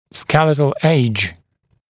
[‘skelɪtl eɪʤ][‘скелитл эйдж]скелетный возраст